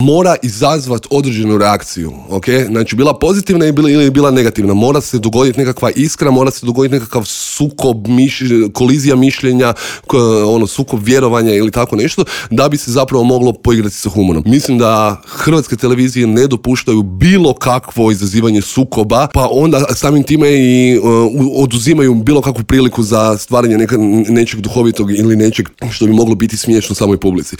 ZAGREB - Novu sezonu intervjua na Media servisu otvorili smo laganom temom.